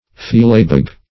philabeg - definition of philabeg - synonyms, pronunciation, spelling from Free Dictionary Search Result for " philabeg" : The Collaborative International Dictionary of English v.0.48: Philabeg \Phil"a*beg\, n. See Filibeg .
philabeg.mp3